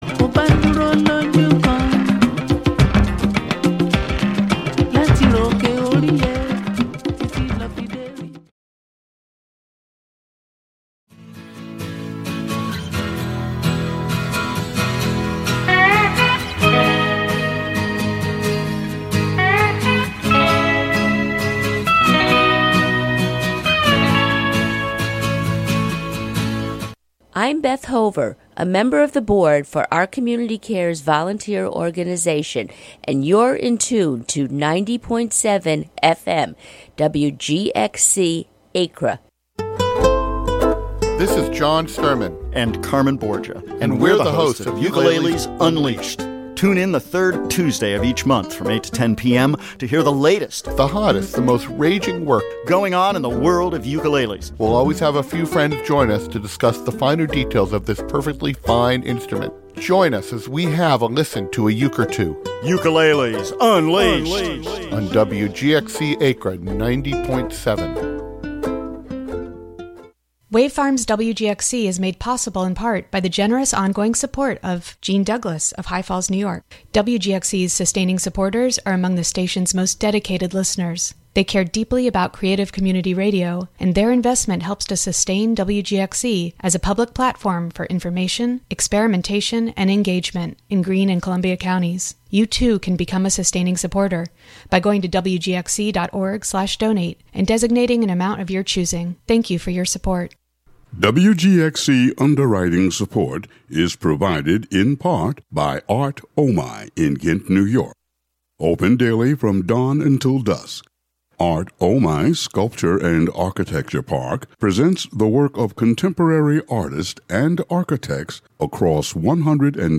A radio series of proprioceptive exercises, interviews about practices of communication, and archival sound. A routine for warming up our means of communication. Presented monthly as a combination of live and prerecorded sessions.